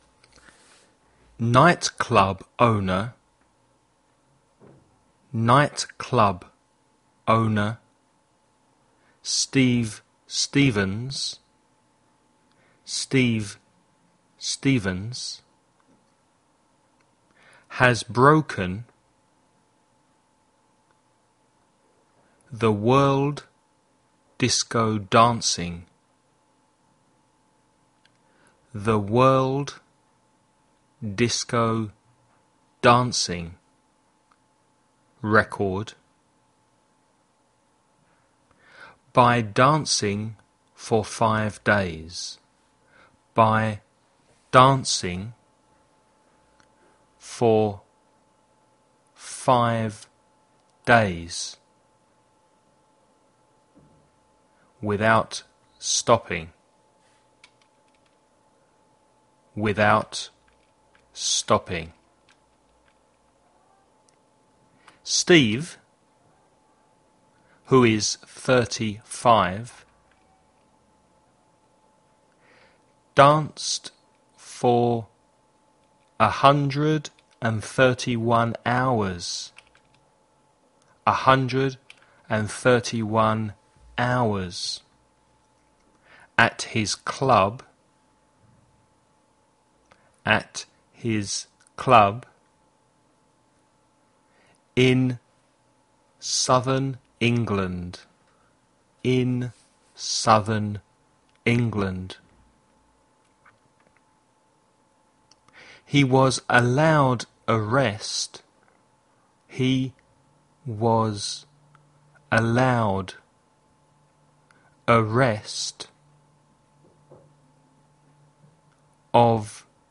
DICTATION / DICTADO
1. Listen to the text read at normal speed.
dictation2.mp3